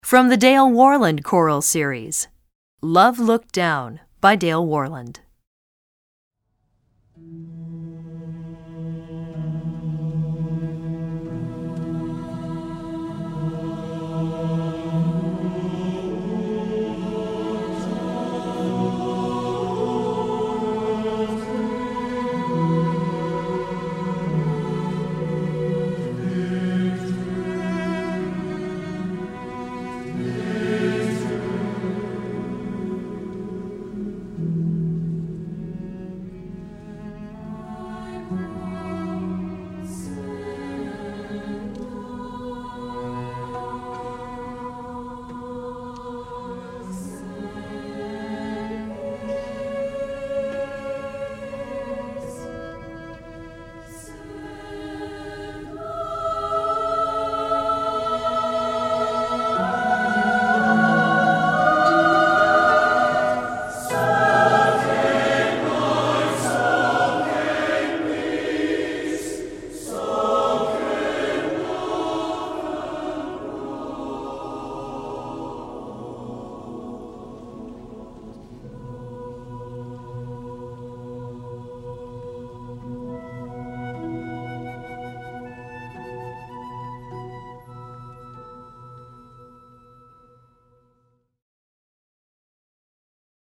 Piano
Bass
Drum Set